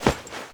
foley_combat_fight_grab_throw_07.wav